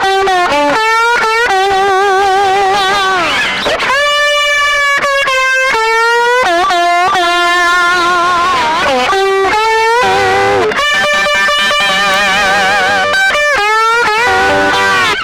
Index of /90_sSampleCDs/Best Service ProSamples vol.17 - Guitar Licks [AKAI] 1CD/Partition D/VOLUME 007